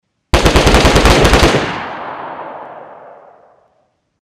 dayz-rapid-lar-gun.mp3